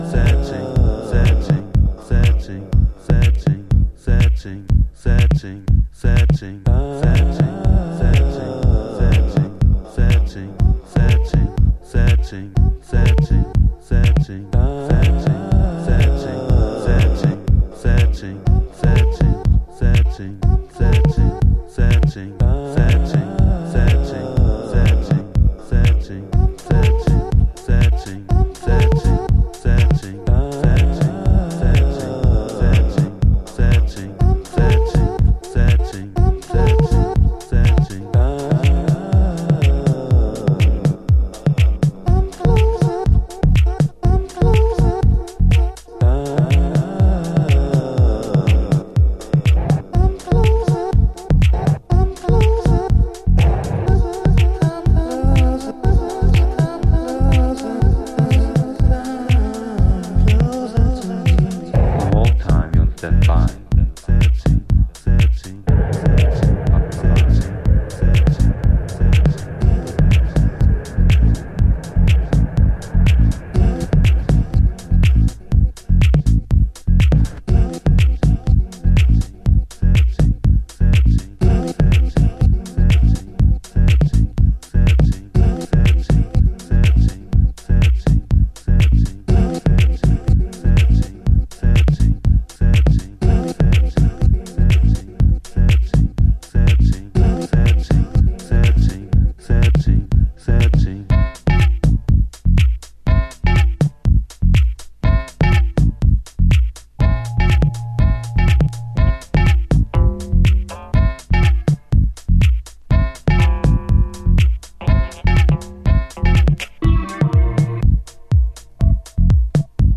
STRANGE MINIMAL HOUSE
House / Techno